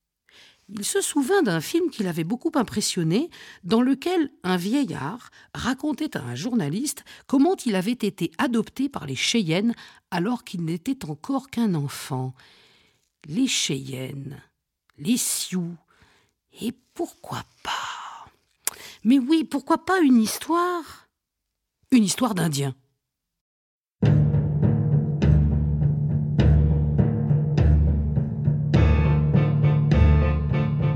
"enPreferredTerm" => "Contes musicaux non classiques"